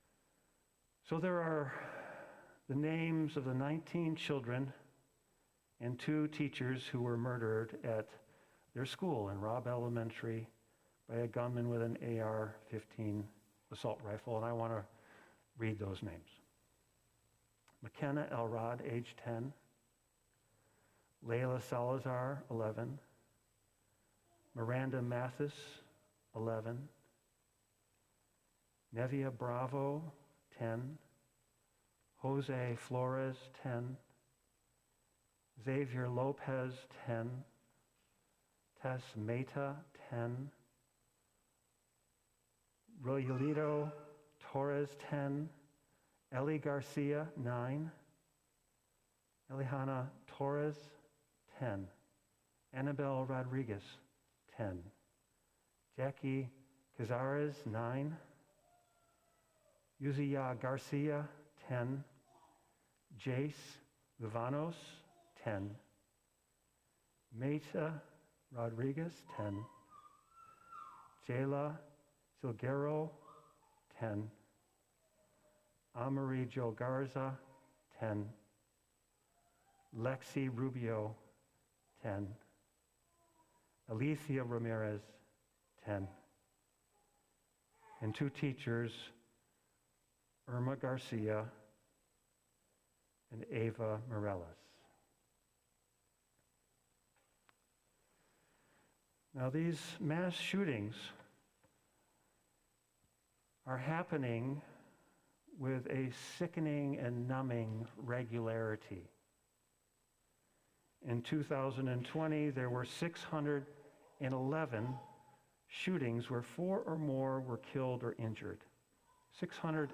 6-5-22-sermon.mp3